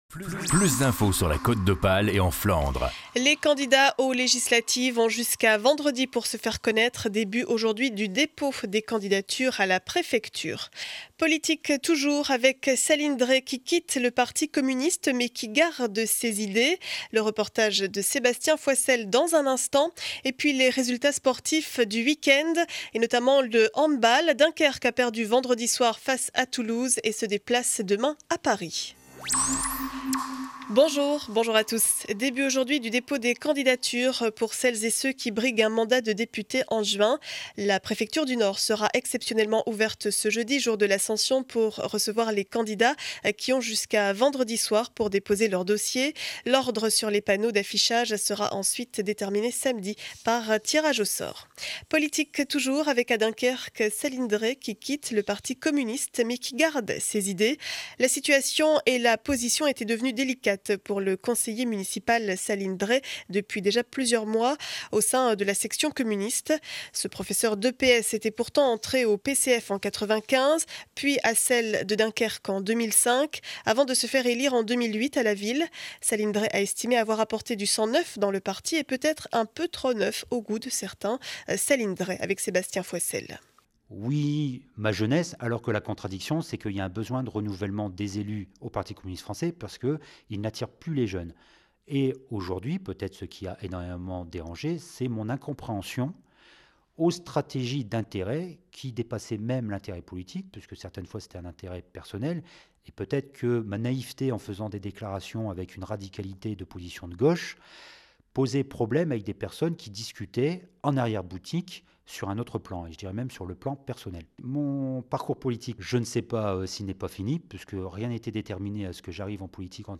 Journal du lundi 14 mai 2012 7 heures 30, édition du Dunkerquois.